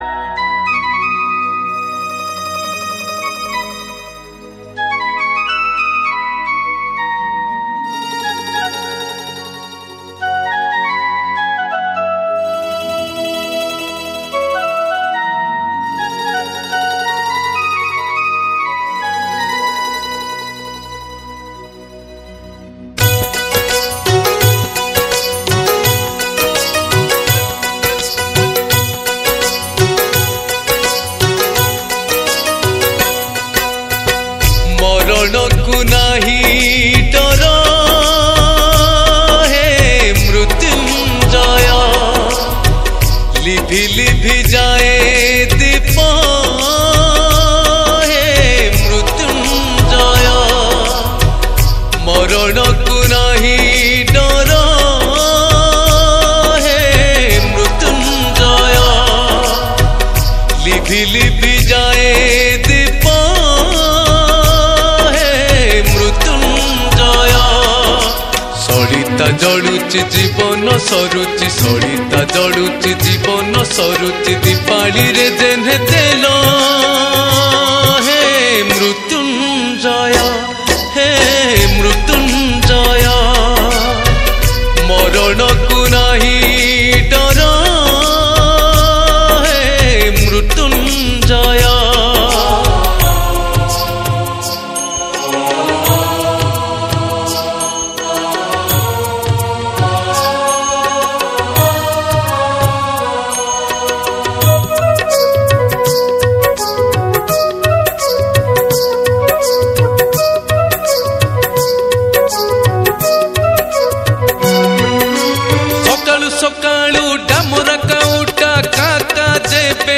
Odia New Bhajan Song